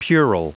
Prononciation du mot puerile en anglais (fichier audio)
Prononciation du mot : puerile